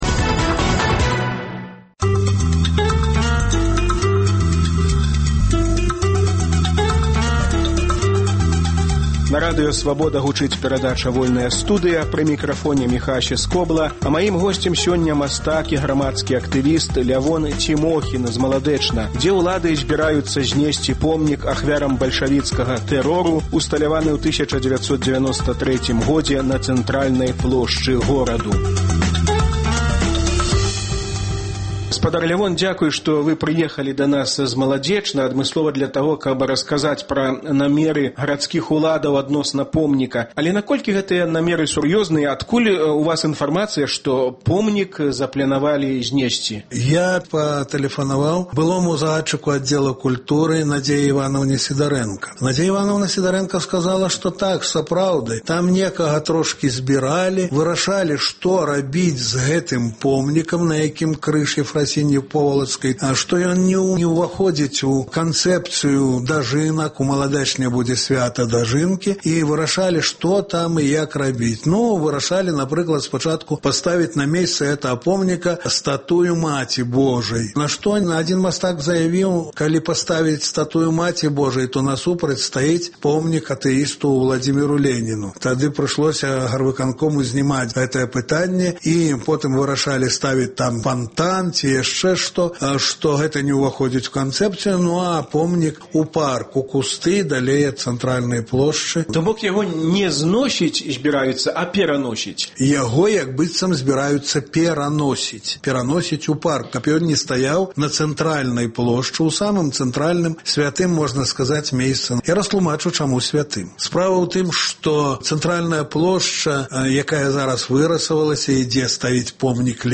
У Маладзечне сёлета маюць адбыцца "Дажынкі", у сувязі з чым гарадзкія ўлады падчас рэканструкцыі плошчы плянуюць зьнесьці помнік ахвярам сталінскага тэрору. У перадачы – гутарка пра гэта з грамадзкім актывістам